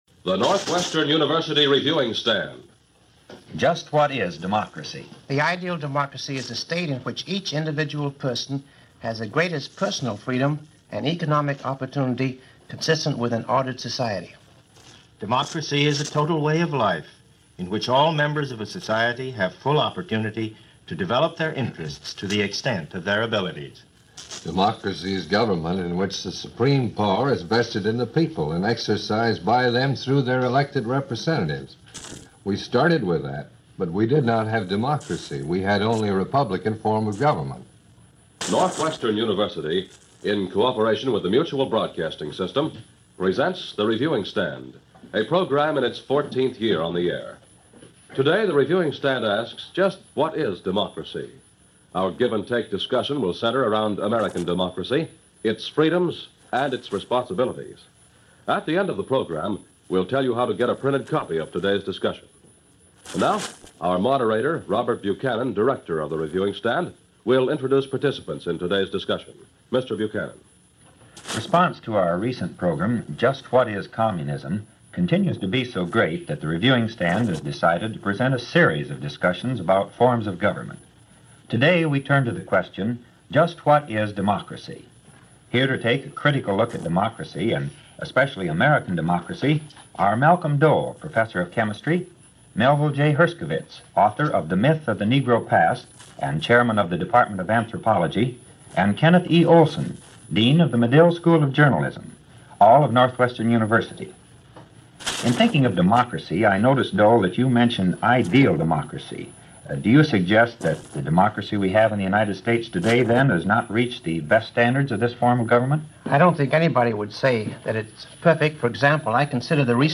As part of the continuing radio series Reviewing Stand from July 4, 1948 – the question is posed to the panel “Just What IS Democracy?” – concentrating on the issue of Democracy pertaining to the U.S. rather than the world.